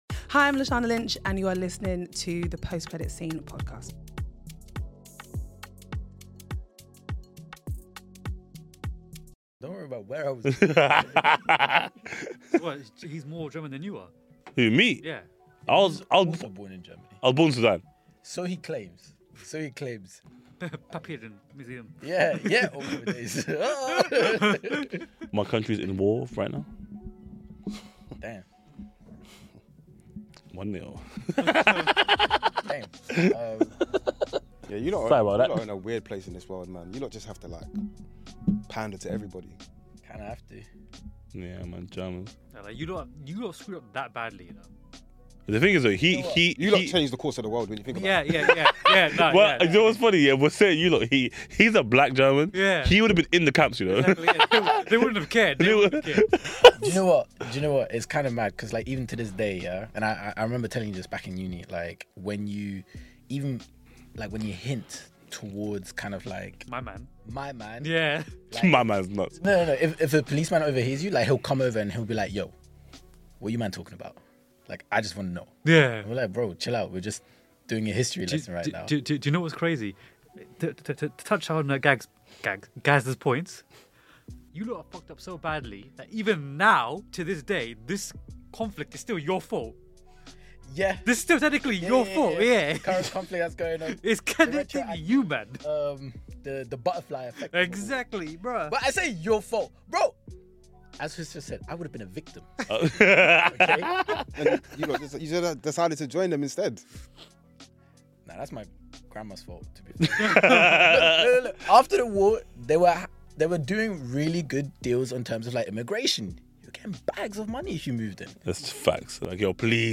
From blockbuster movies to series gems, the hosts bring their unique perspectives, humour, and passion to every episode, making each conversation as entertaining as it is informative.